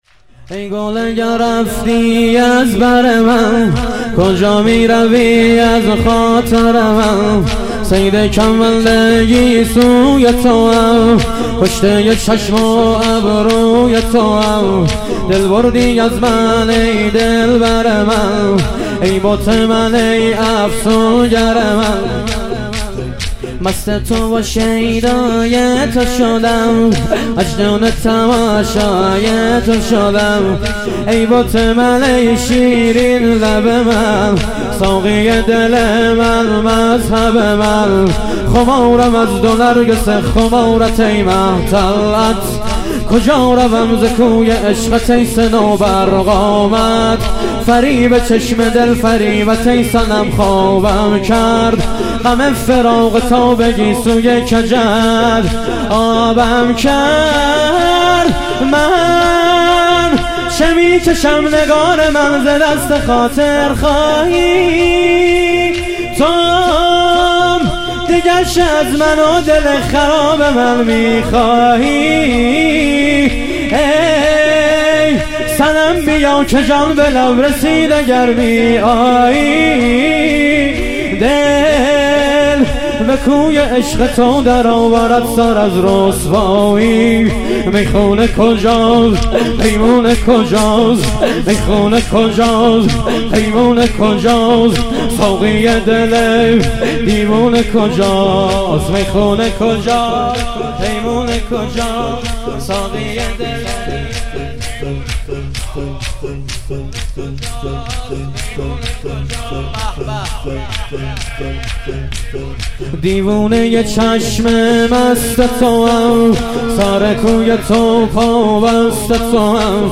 ظهور وجود مقدس امام حسن عسکری علیه السلام - شور